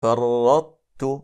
8. Ţā' [الطاء — ط] in i Tā' [التاء — ت]:
Det är ofullständig idgham (إدغامٌ ناقص); eftersom en stark bokstav inte går in helt i en svag bokstav, så brukade araberna slå samman det icke-vokaliska (Ţā' — ط) i (Tā' — ت), samtidigt som det bevarade egenskapen av vidhäftning (iţbāq — الإطباق) från det, vilket händer när reciteraren stänger hans/hennes tunga på en (Ţā' — ط) utan qaqalah och sedan tar han/hon bort den från en vokal (Tā' — ت), som i: